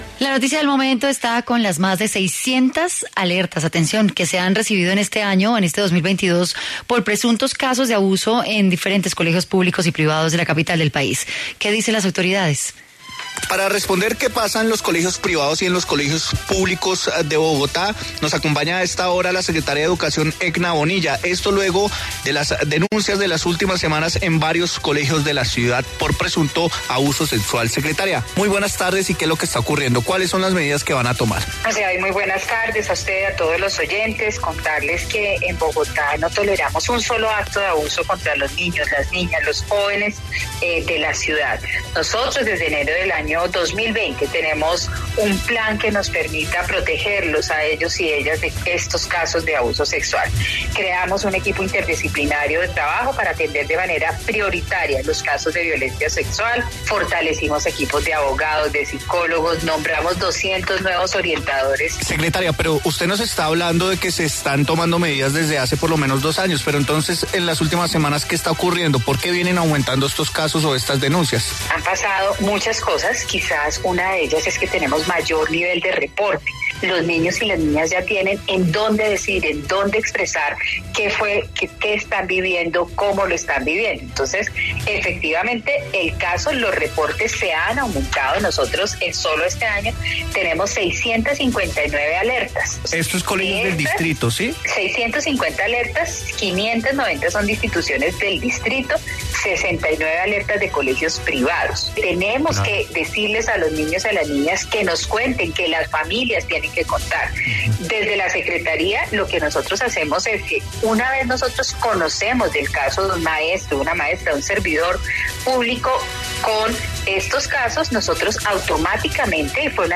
En diálogo con W radio, la secretaria de Educación dio a conocer cuáles serán las estrategias del Distrito ante estos casos.